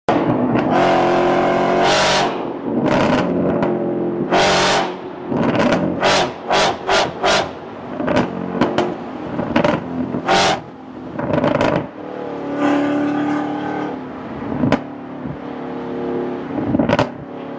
🔥 Chevrolet Camaro ZL1 – Exhaust of Pure American Fury 🔊 6.2L Supercharged LT4 V8 spitting thunder with every rev. Raw, aggressive, and built to dominate the streets.